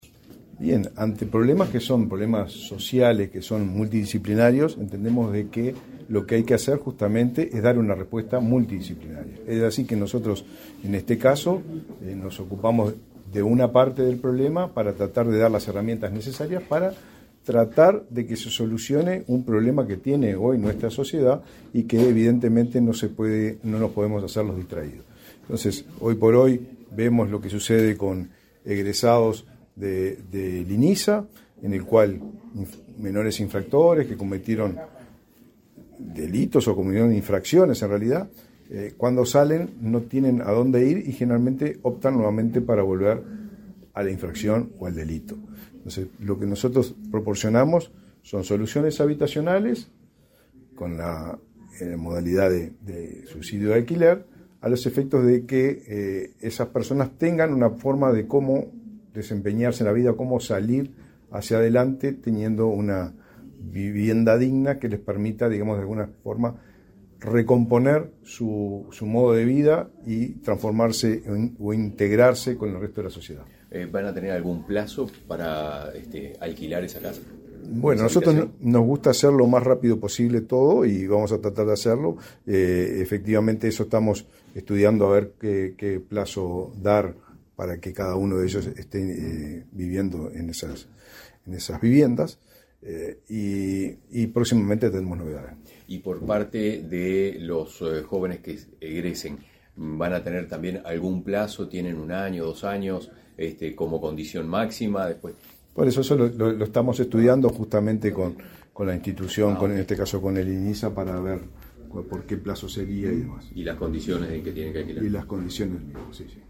Declaraciones a la prensa del ministro del MVOT, Raúl Lozano
Tras participar en la firma de convenio entre el Ministerio de Desarrollo Social (Mides), el Ministerio de Vivienda y Ordenamiento Territorial (MVOT) y el Instituto Nacional de Inclusión Social Adolescente (Inisa), este 29 de agosto, para que adolescentes y jóvenes asistidos por el Inisa accedan a alternativas habitacionales y asesoramiento para su reinserción, el ministro Raúl Lozano realizó declaraciones a la prensa.
Lozano prensa.mp3